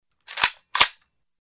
Звуки перезарядки оружия
Звук перезарядки автомата Калашникова АК 47